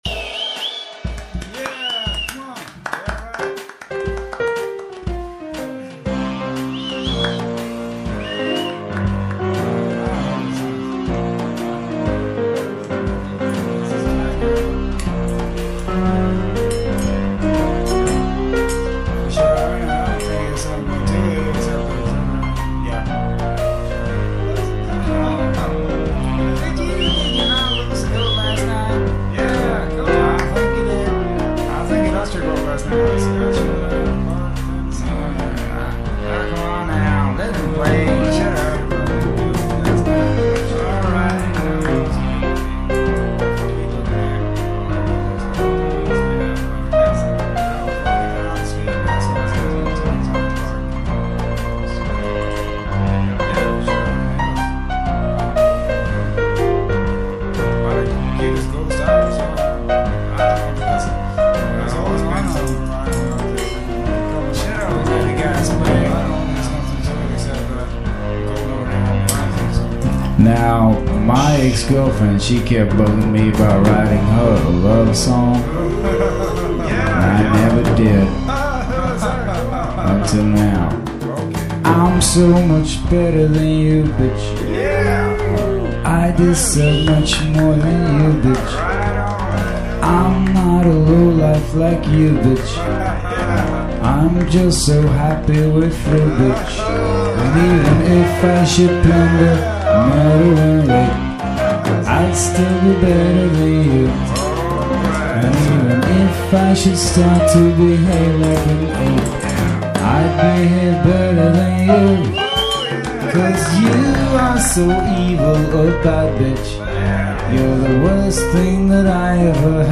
(Slow jazz)
Listen (recorded live at a sleezy bar in Alabama)